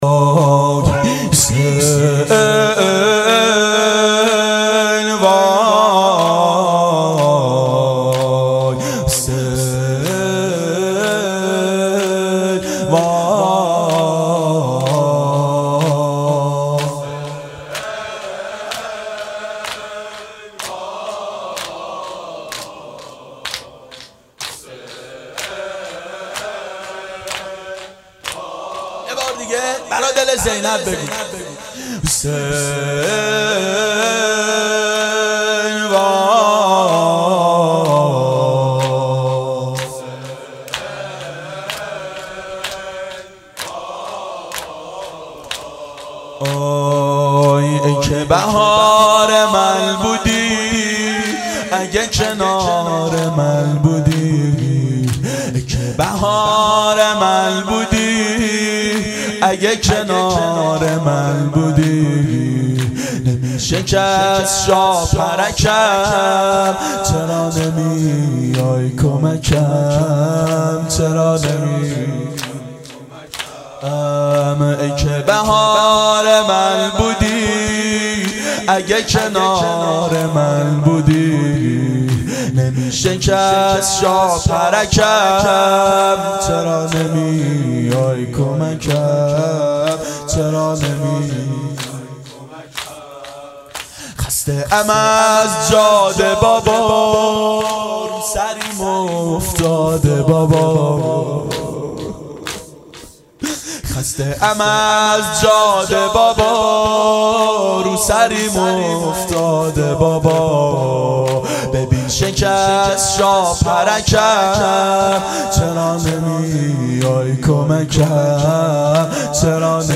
دهه اول صفر سال 1390 هیئت شیفتگان حضرت رقیه س شب دوم (شب شهادت)